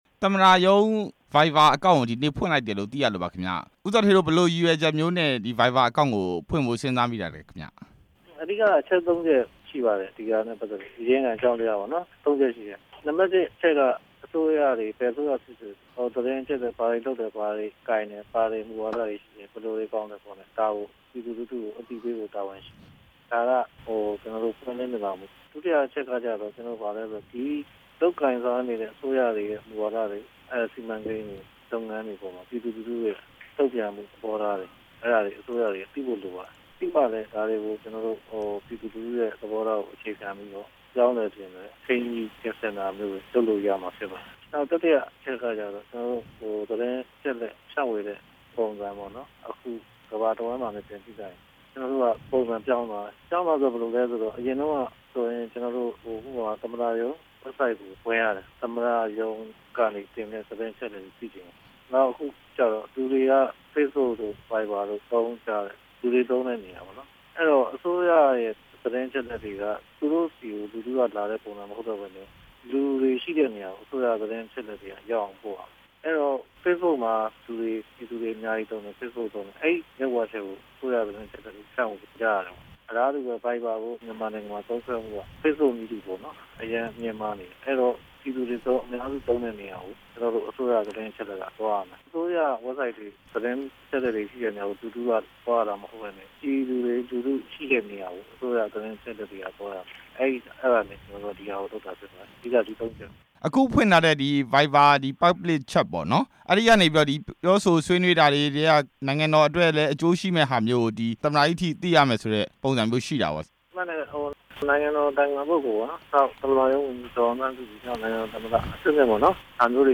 ဦးဇော်ဌေး ကို ဆက်သွယ်မေးမြန်းချက်